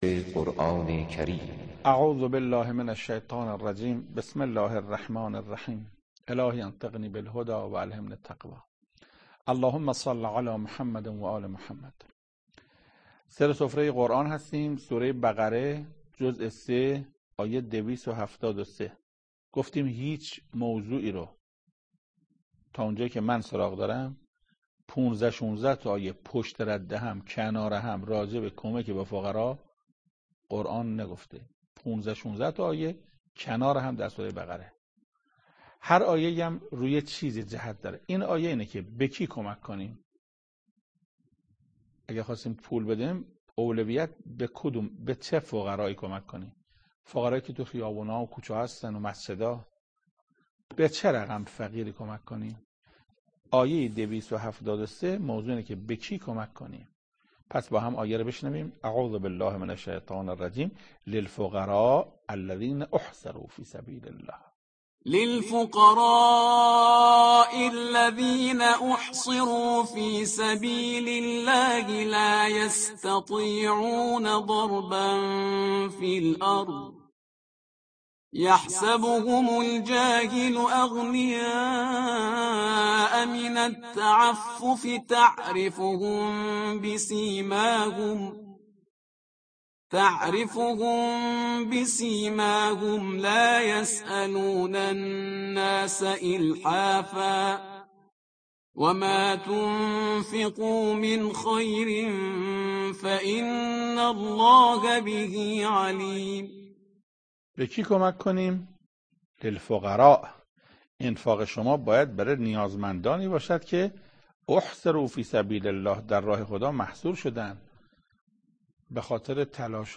تفسیر دویست و هفتاد و سومین آیه از سوره مبارکه بقره توسط حجت الاسلام استاد محسن قرائتی به مدت 10 دقیقه